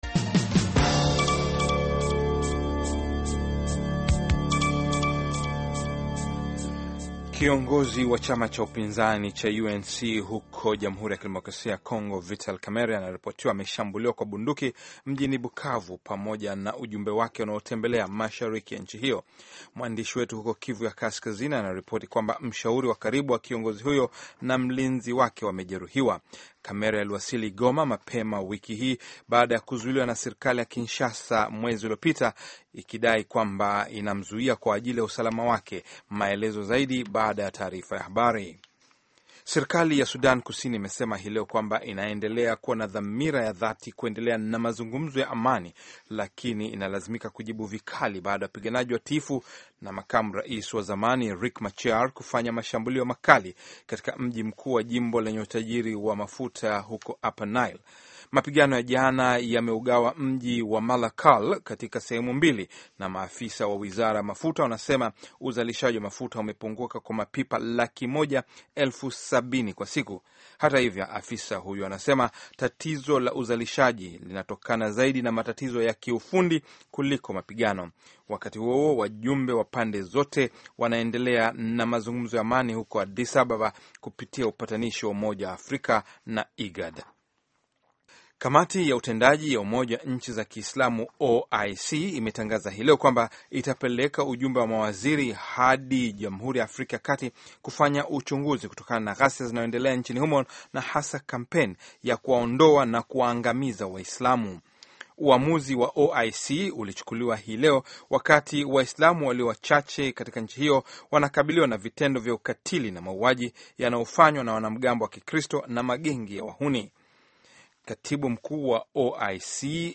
Taarifa ya Habari VOA Swahili - 4:46